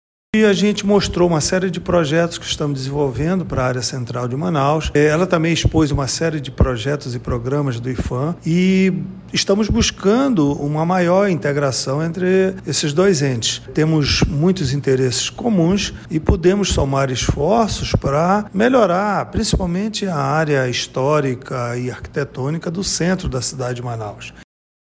Sonora-1-Carlos-Valente-diretor-presidente-do-Implurb.mp3